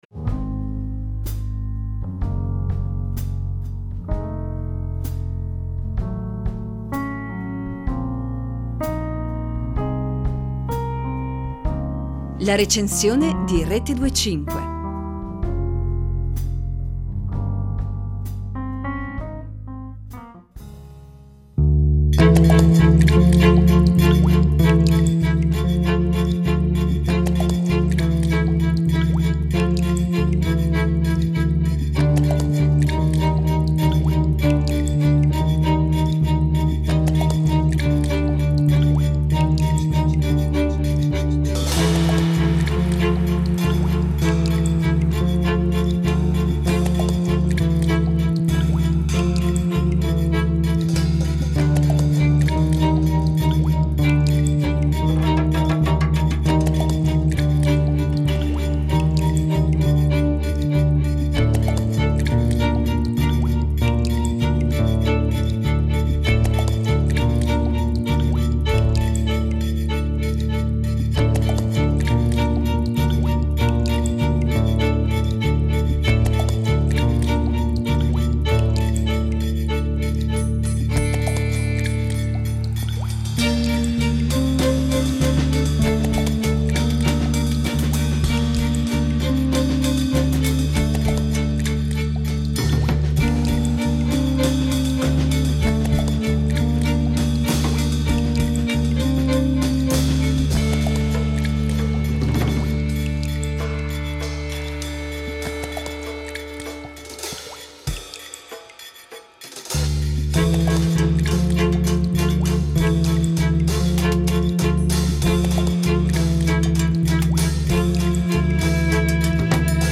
bassi profondi